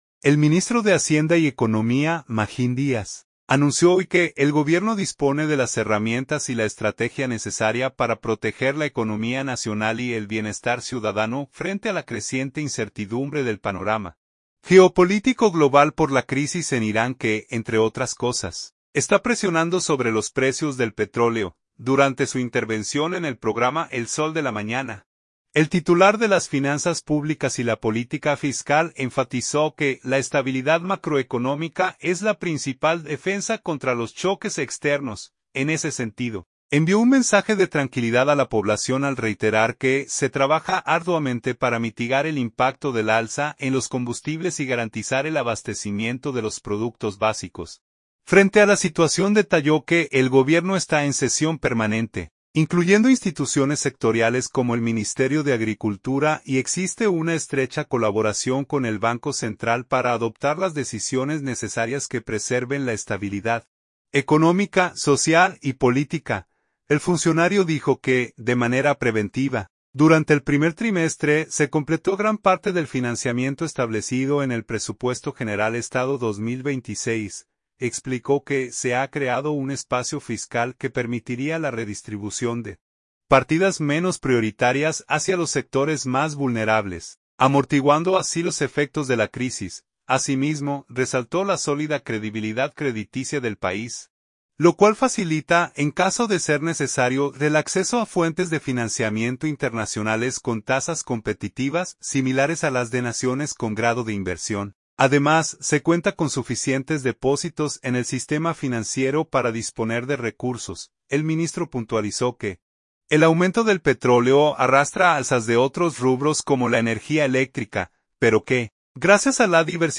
Durante su intervención en el programa "El Sol de la Mañana", el titular de las finanzas públicas y la política fiscal enfatizó que la estabilidad macroeconómica es la principal defensa contra los choques externos.